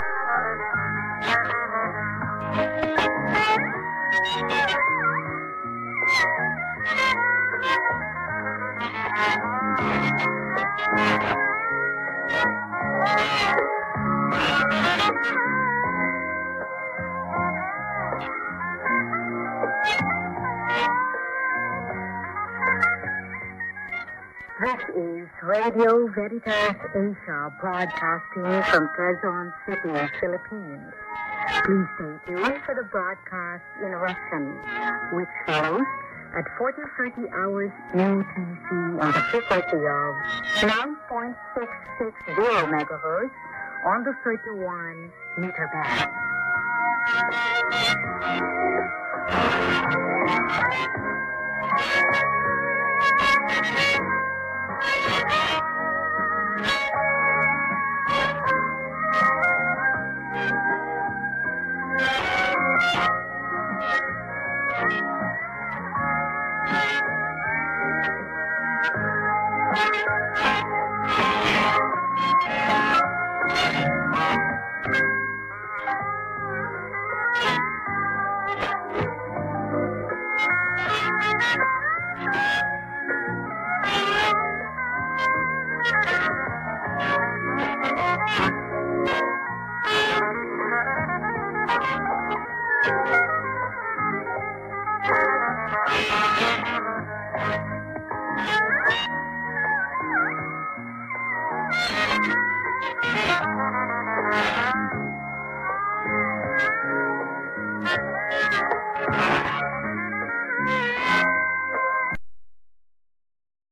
安価なポケットラジオのような音域の狭いキンキンする高音中心の音。
AMモードではフェーディングに伴い音が異様に割れると同時に音量が不自然に変わる。プツプツという人工的なノイズも発生。